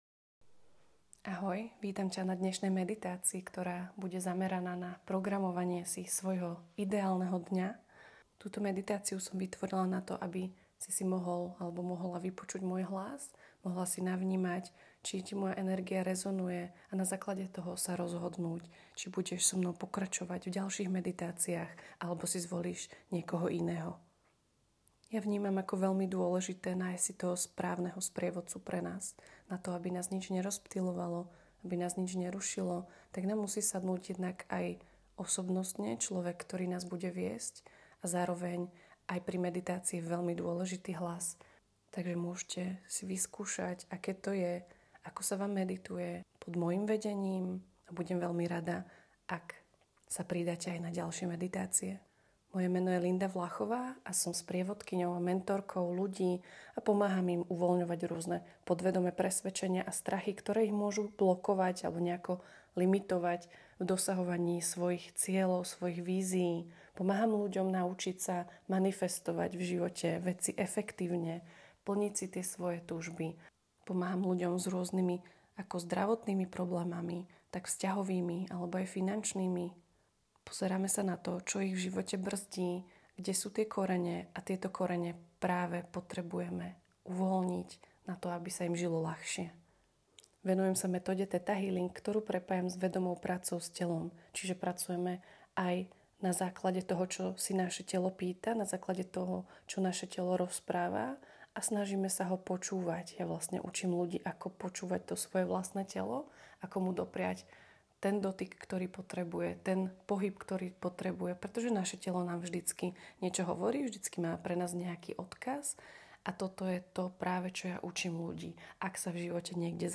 Táto meditácia vznikla za účelom toho, aby si si mohol/mohla vypočuť môj hlas, vyskúšať si, ako sa cítiš pod mojim vedením, navnímať si ma ako osobu a ľahsie sa tak rozhodnúť, či si zakúpiš aj ďalšie meditácie z mojej ponuky.
Ranna-meditacia-Programovanie-idealneho-dna.mp3